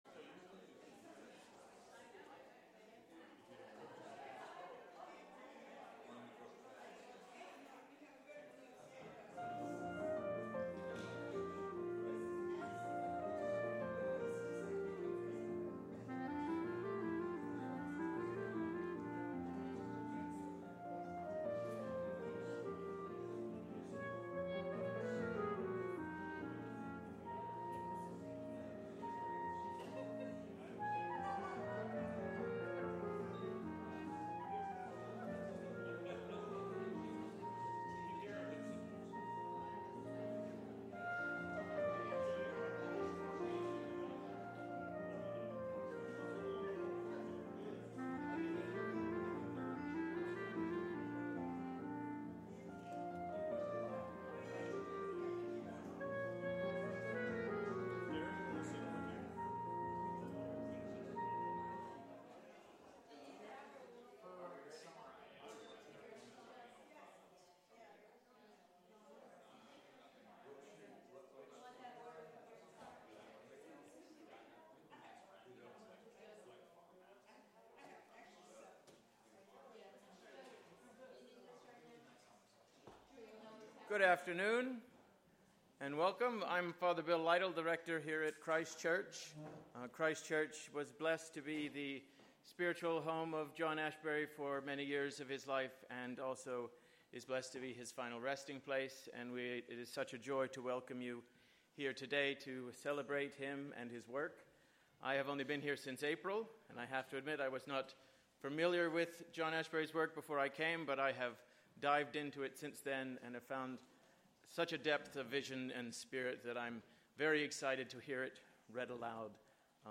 Live from The Flow Chart Foundation
A celebration reading for John Ashbery’s 98th birthday with a series of poets reading some of his works at the Christ Church in Hudson, where John was a long-time parishioner, and his final resting place.